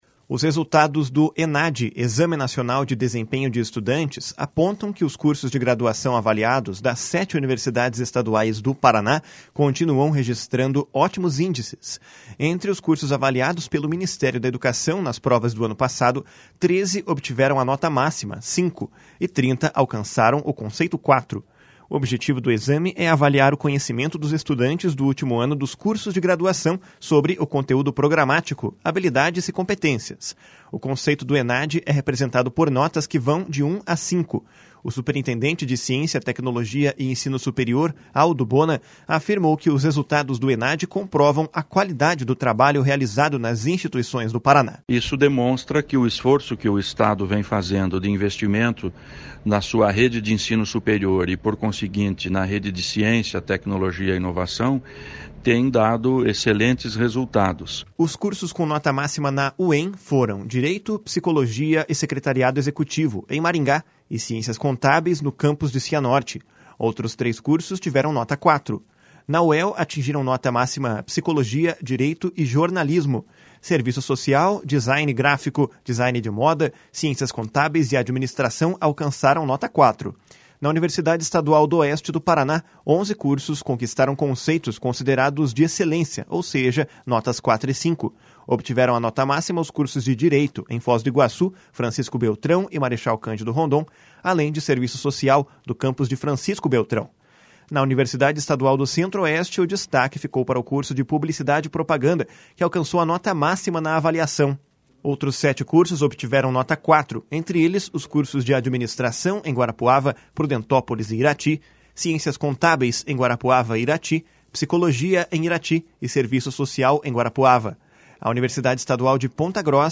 O superintendente de Ciência, Tecnologia e Ensino Superior, Aldo Bona, afirmou que os resultados do Enade comprovam a qualidade do trabalho realizado nas instituições. // SONORA ALDO BONA //